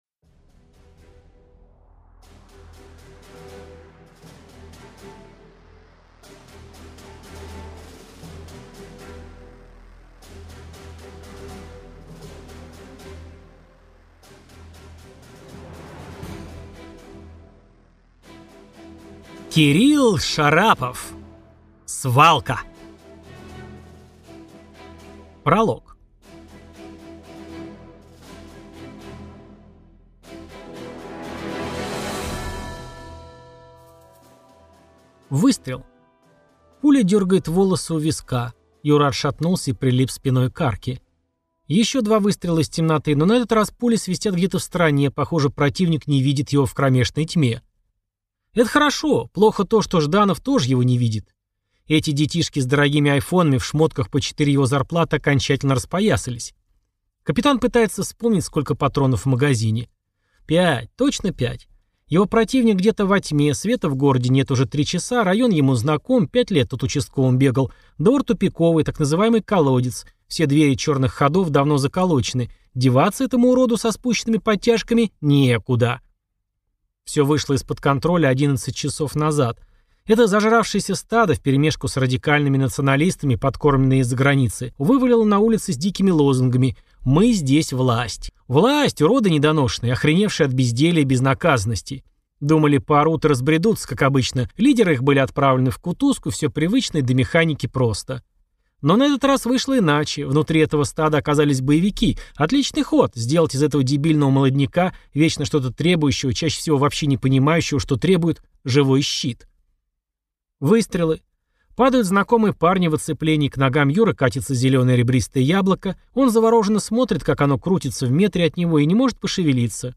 Аудиокнига Свалка | Библиотека аудиокниг